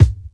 KICK_WIN_THE_WAR.wav